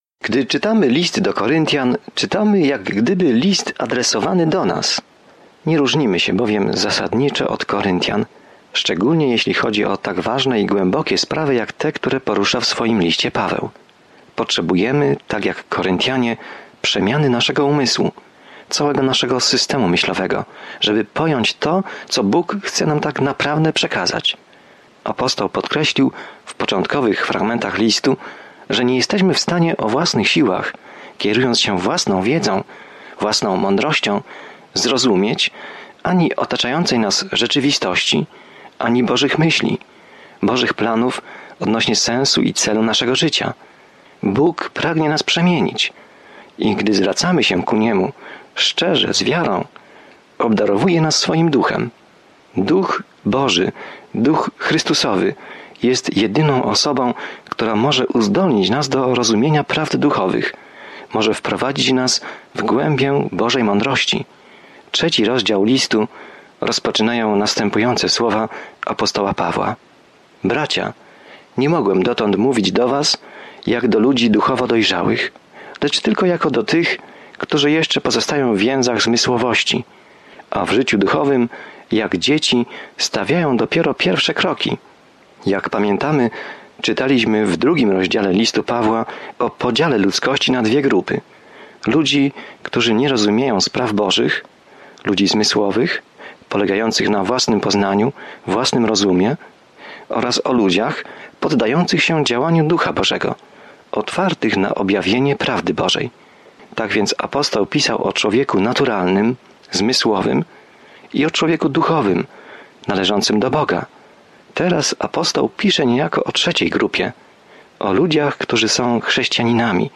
Jest to temat poruszony w Pierwszym Liście do Koryntian, zawierający praktyczną opiekę i korektę problemów, przed którymi stają młodzi chrześcijanie. Codziennie podróżuj przez 1 List do Koryntian, słuchając studium audio i czytając wybrane wersety słowa Bożego.